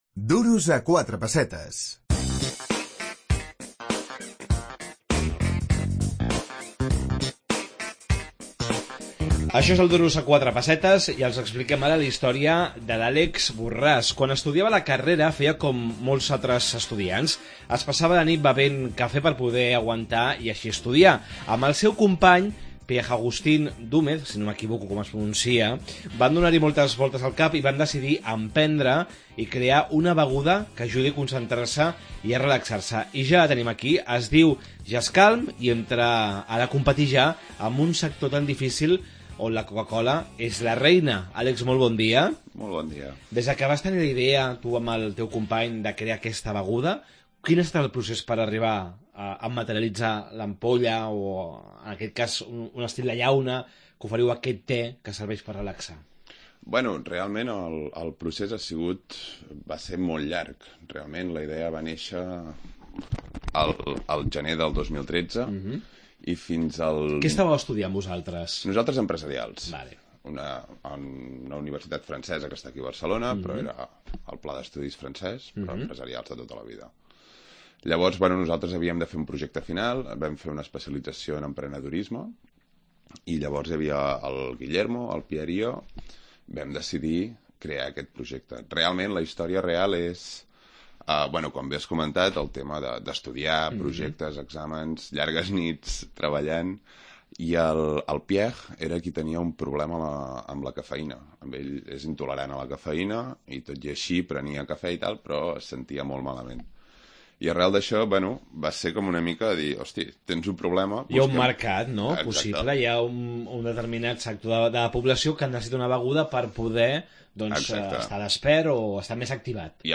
La història d'uns emprenedors catalans que han creat una beguda de té. Com han pensat competir amb un gegant com la Cocacola? Entrevista